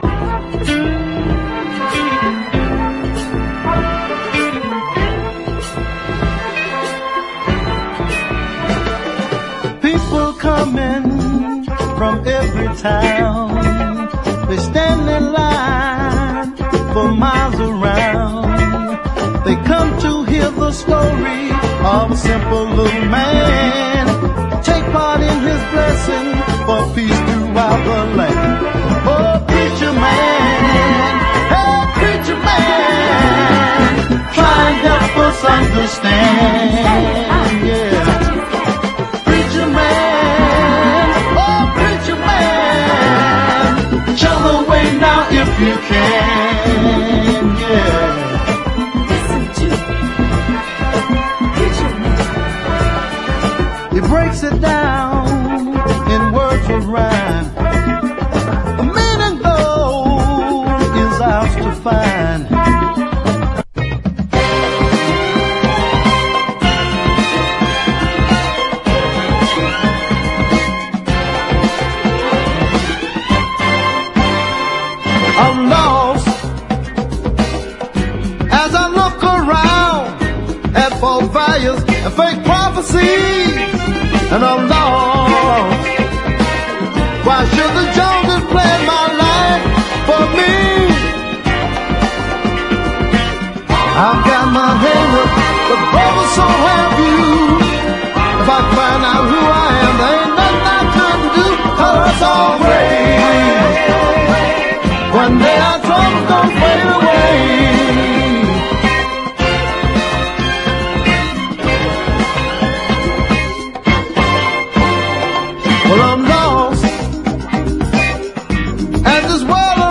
EASY LISTENING / OTHER / OLDIES / RHYTHM & BLUES / SAX
オールディーズDJも要チェックの楽しいホンカー！
US ORIGINAL盤 MONO！